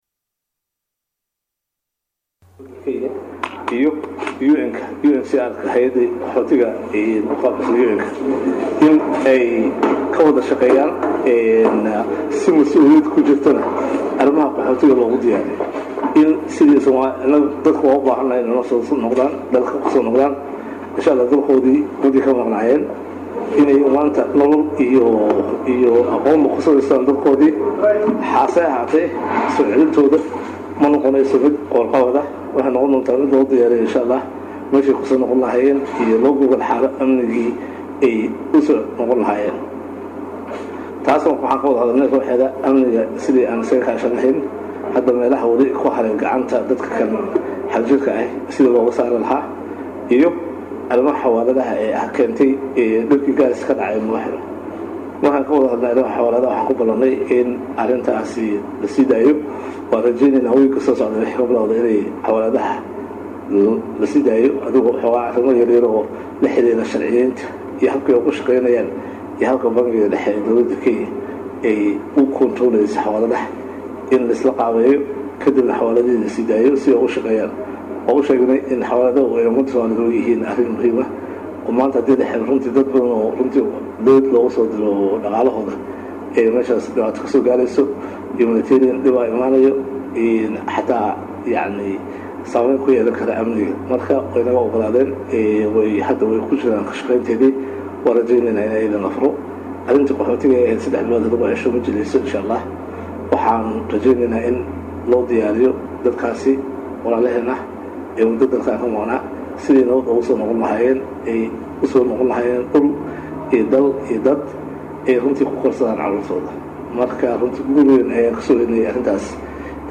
Dhageyso: R/W Sharmaake oo shir Jaraa’id kaga hadley arrimaha soo celinta Qaxootiga, Xawaaladaha & Xasuuqa ka socda South Afrika